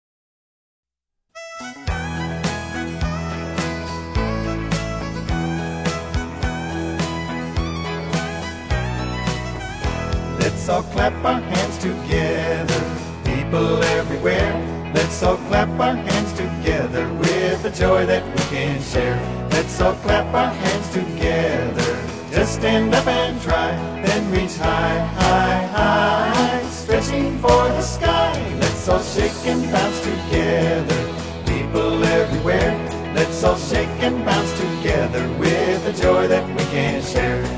A Fun Movement Song